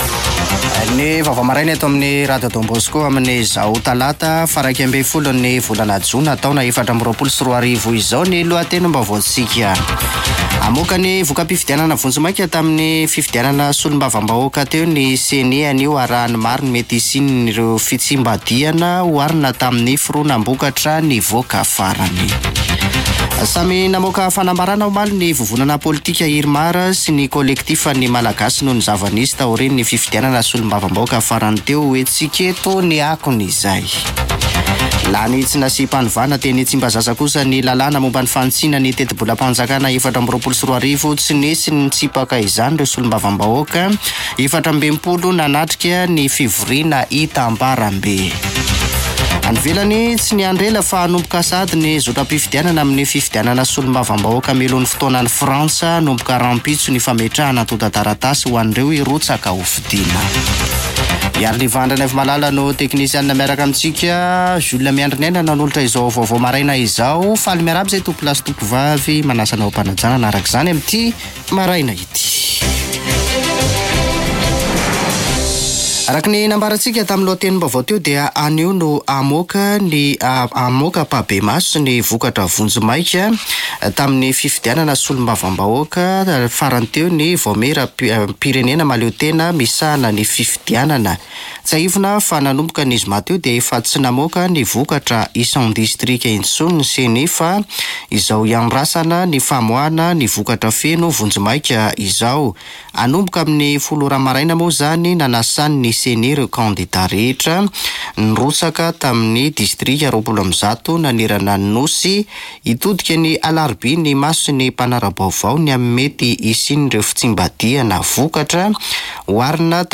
[Vaovao maraina] Talata 11 jona 2024